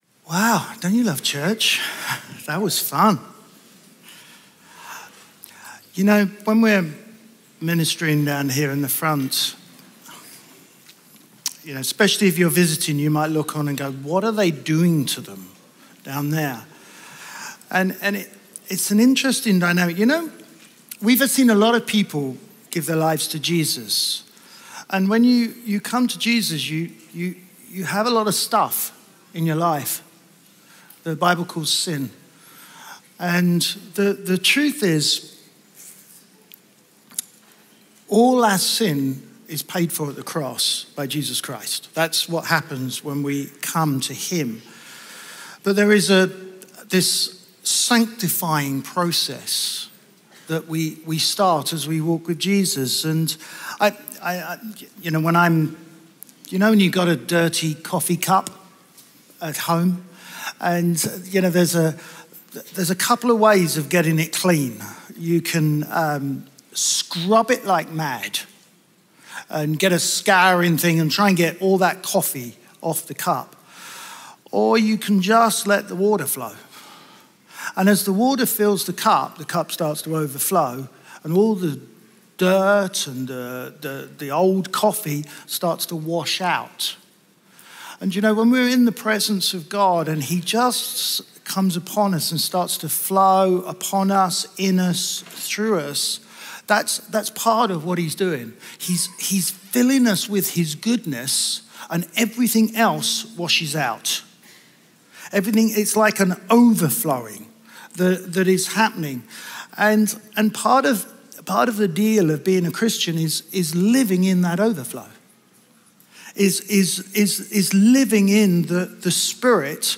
Chroma Church - Sunday Sermon Deborah – knowing who you are May 15 2023 | 00:31:33 1x 00:00 / 00:31:33 Subscribe Share RSS Feed Share Link Embed